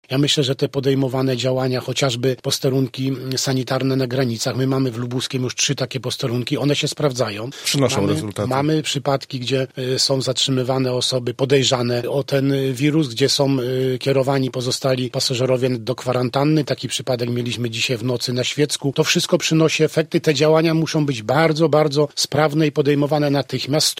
Działania prewencyjne, jak zwraca uwagę poranny gość Radia Zachód, przynoszą pierwsze efekty: